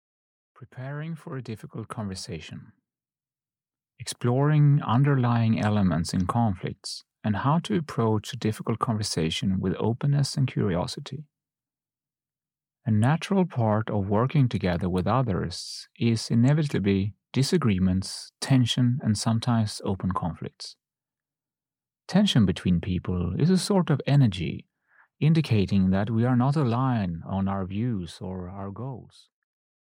Preparing for a Difficult Conversation (EN) audiokniha
Ukázka z knihy